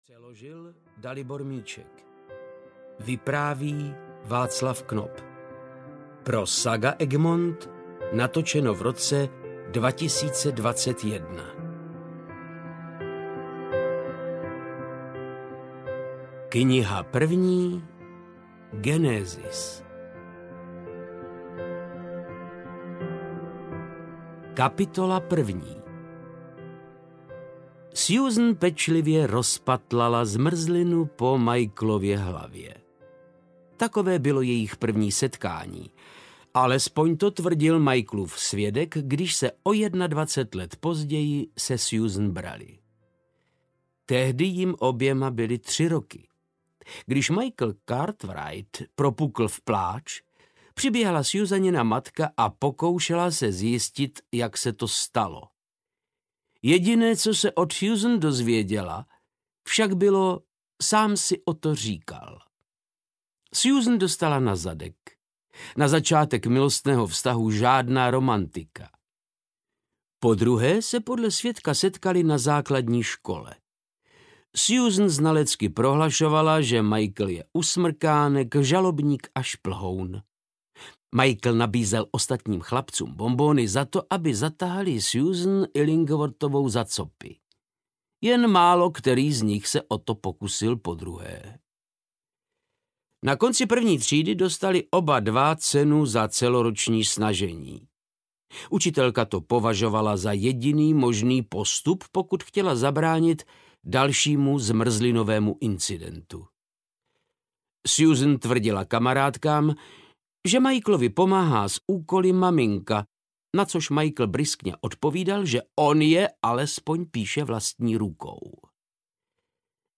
Synové štěstěny audiokniha
Ukázka z knihy
• InterpretVáclav Knop
synove-stesteny-audiokniha